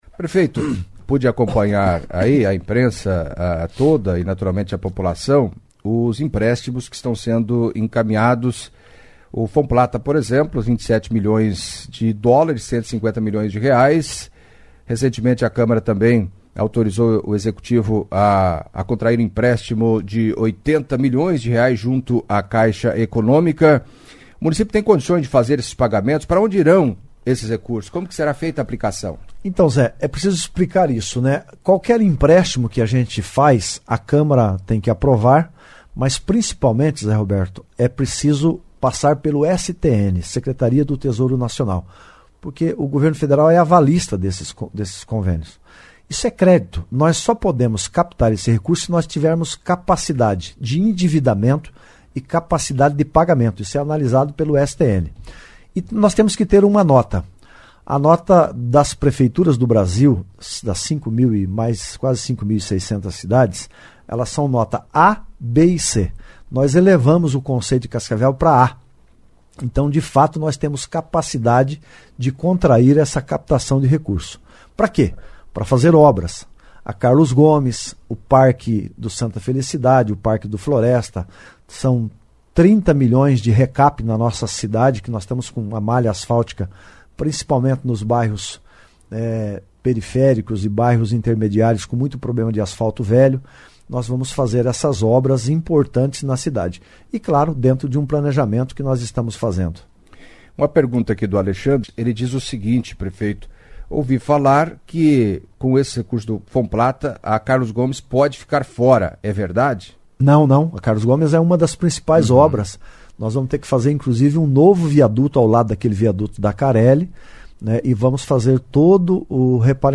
Em entrevista à CBN Cascavel nesta quinta-feira (30) o prefeito Paranhos apresentou um balanço dos trabalhos de 2021 e respondeu perguntas de ouvintes. Inicialmente, falou da reunião com vereadores ocorrida ontem, que durou mais de cinco horas, sobre a importância do projeto de emenda à Lei Orgânica do município.